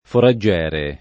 foraggiere [ fora JJ$ re ] s. m. (stor.